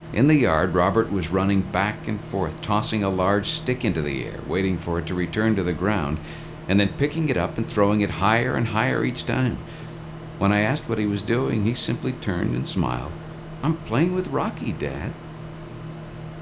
Source: female-clean.wav
Table 1: Examples of speech which has been phase scrambled within overlapping windows of varying length